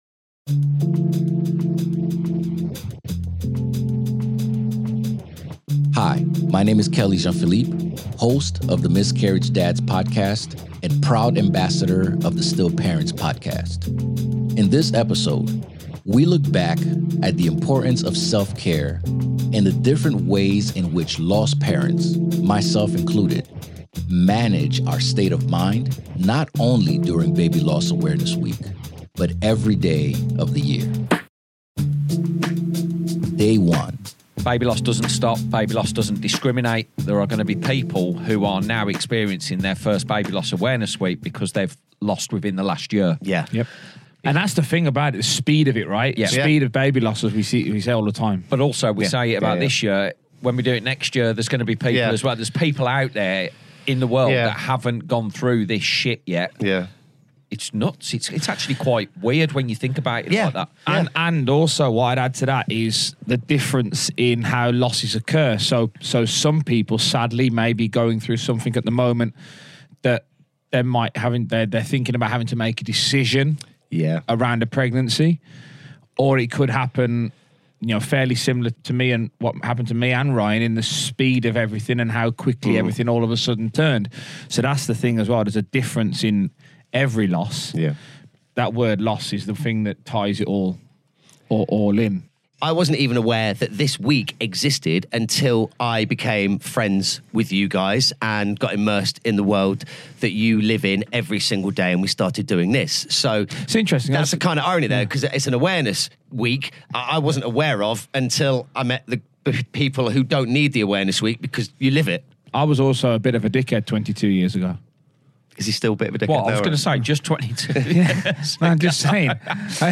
Looking back at BLAW 2024 featuring clips from all our guests across the week.